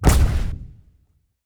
pgs/Assets/Audio/Sci-Fi Sounds/Weapons/Sci Fi Explosion 11.wav at master
Sci Fi Explosion 11.wav